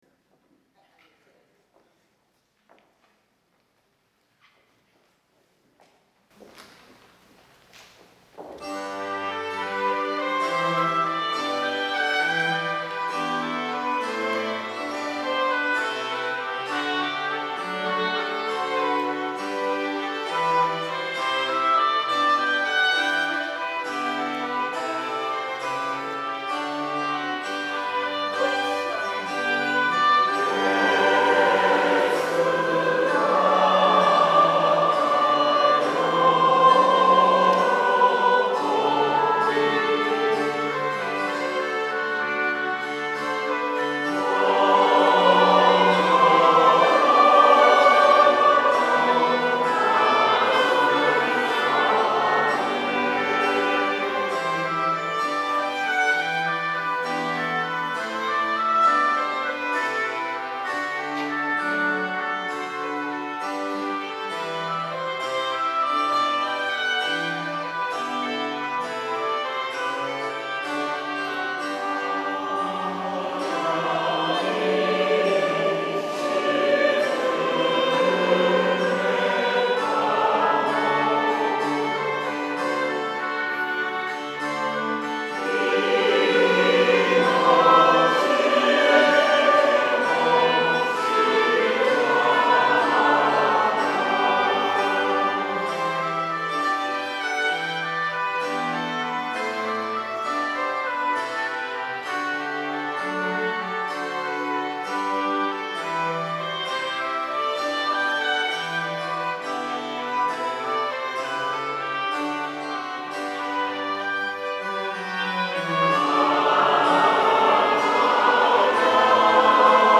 B.C.T.
at Hopkusai Hall